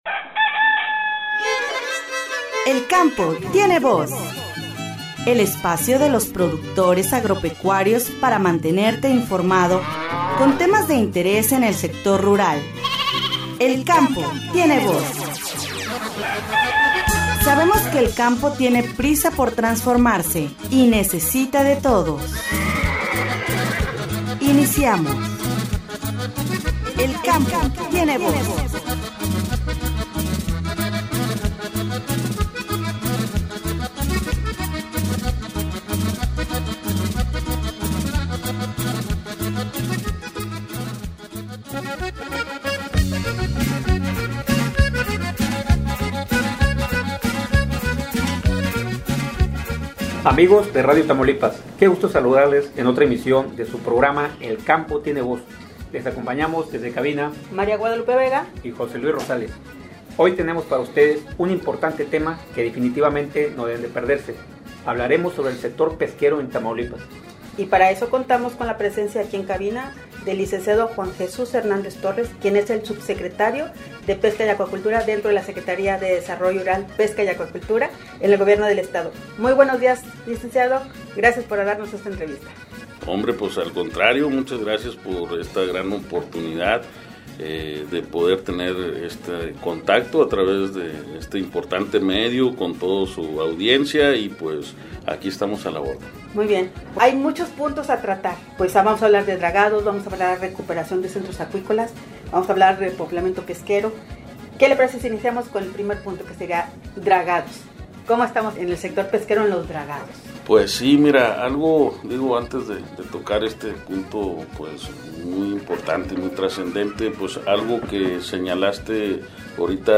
“El Campo Tiene Voz”, en esta ocasión se entrevistó al Subsecretario de Pesca y Acuacultura, Lic. Juan Jesús Hernández Torres, quien nos habló sobre que son los dragados, y en qué consisten y en que municipios se han llevado acabo.